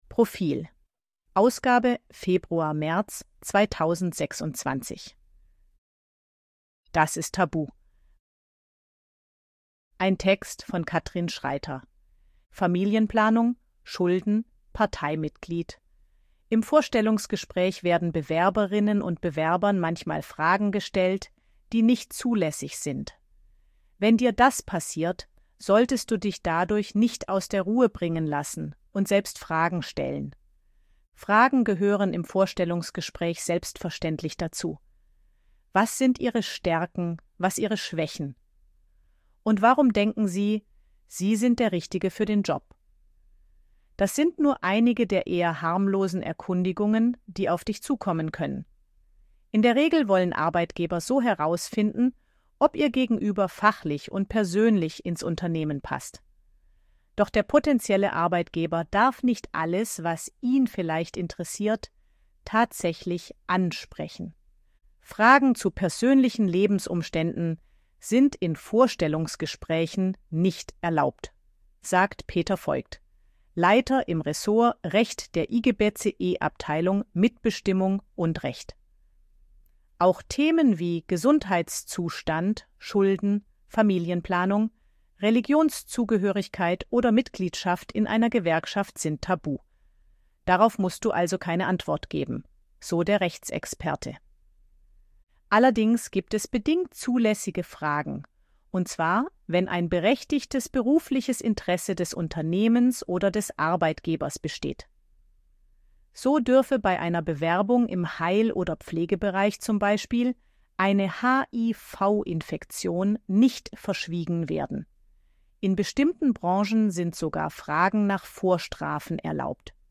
ElevenLabs_261_KI_Stimme_Frau_Service_Arbeit.ogg